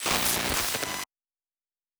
pgs/Assets/Audio/Sci-Fi Sounds/Electric/Glitch 2_09.wav at master
Glitch 2_09.wav